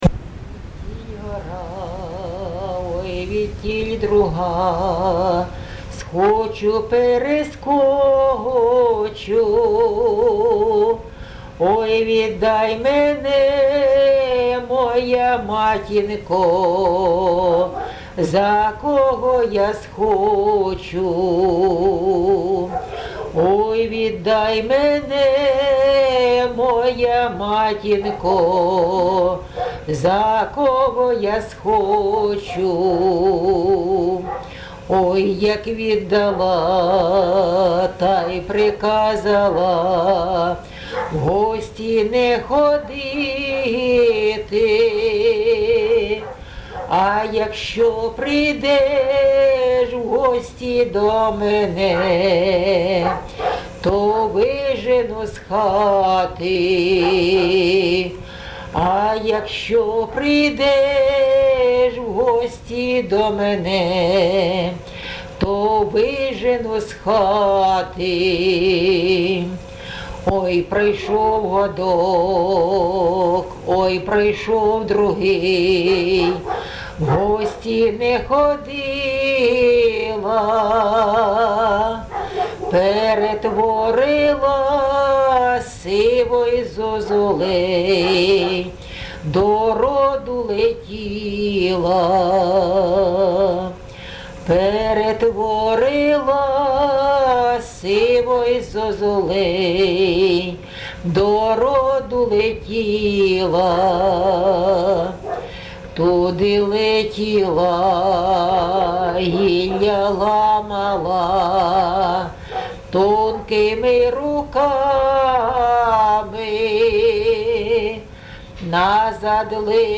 ЖанрПісні з особистого та родинного життя
Місце записус. Михайлівка, Олександрівський (Краматорський) район, Донецька обл., Україна, Слобожанщина